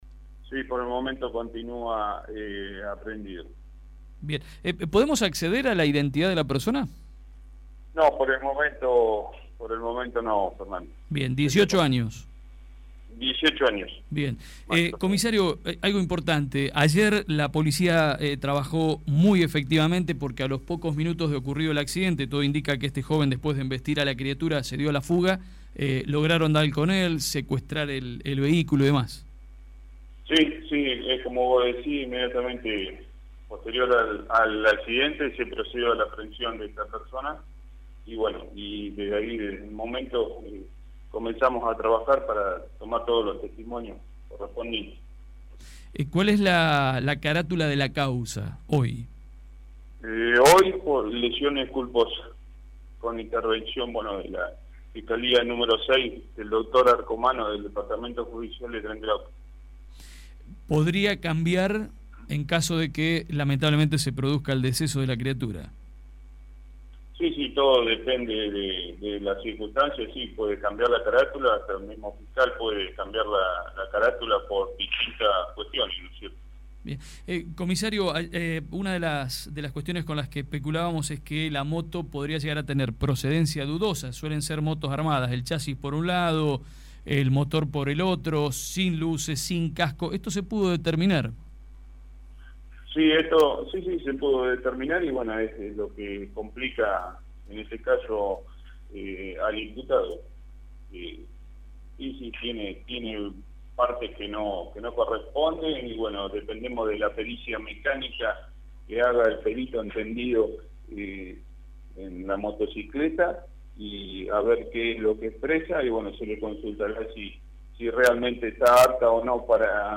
El jefe de la estación de Policía Comunal, comisario habló esta mañana en FM Peregrina (92.9), donde detalló de qué manera la Policía llevó a cabo el procedimiento para dar con el motociclista que anoche...